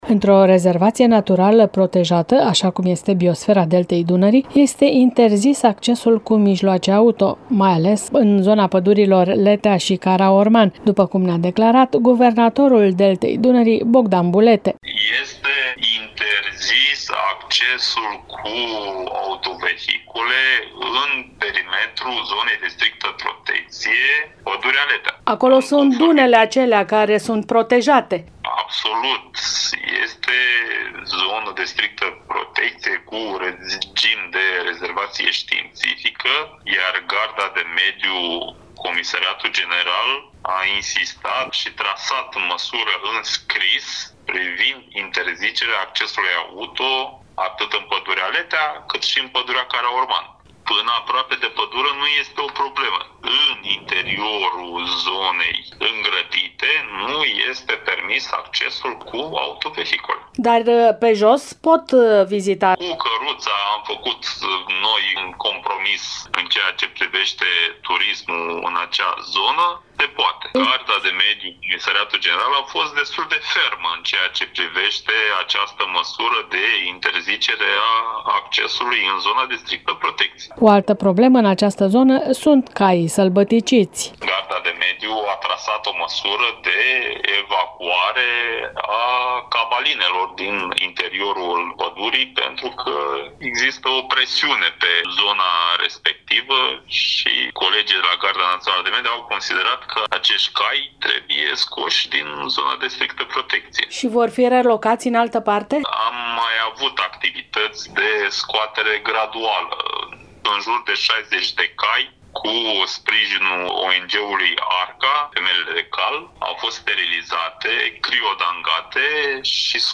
Guvernatorul ARBDD, Bogdan Bulete, ne-a declarat că pentru a ajunge în pădurea Letea se pot folosi mijloace de transport tradiționale, cum sunt căruțele, sau se poate merge pe jos, astfel încât turismul nu este afectat.